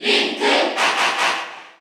Crowd cheers (SSBU)
Link_&_Toon_Link_Cheer_Korean_SSBU.ogg